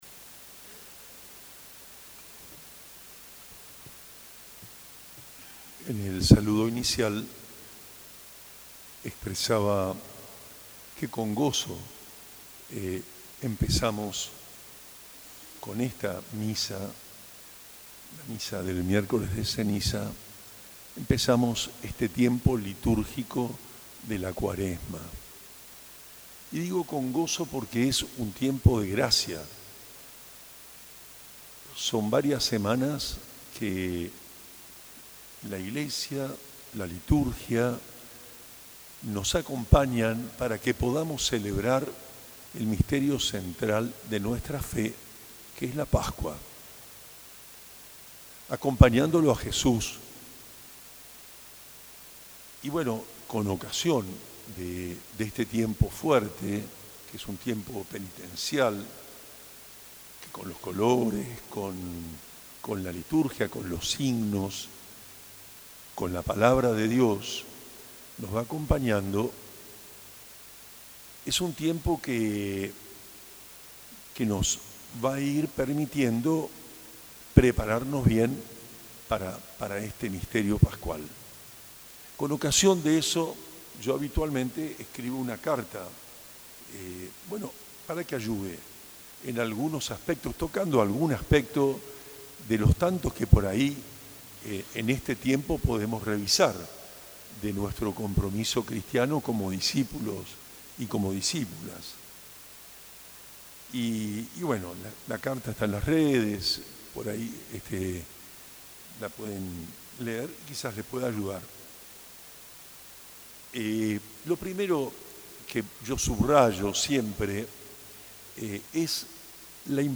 El pasado 5 de marzo de 2025, monseñor Juan Rubén Martínez, obispo de la Diócesis de Posadas, celebró la Santa Eucaristía en la Catedral San José ante una multitud de fieles que se congregaron para la misa del Miércoles de Ceniza.
Homilia.mp3